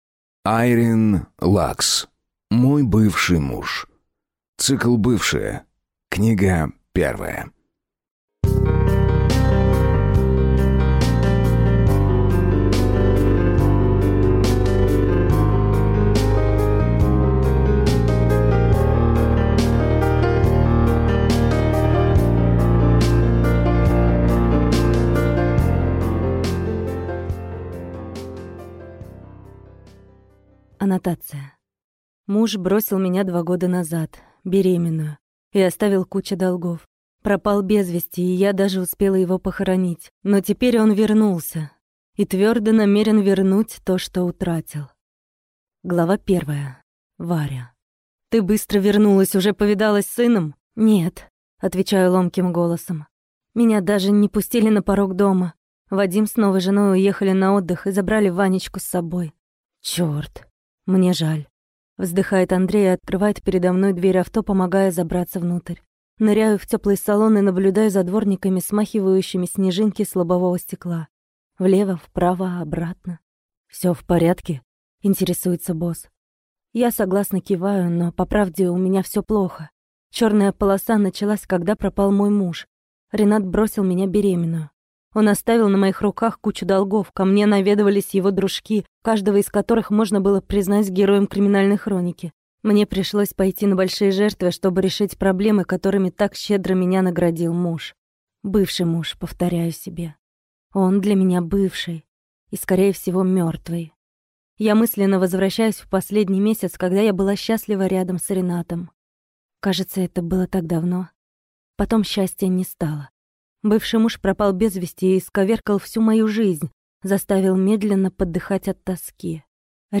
Аудиокнига Мой бывший муж | Библиотека аудиокниг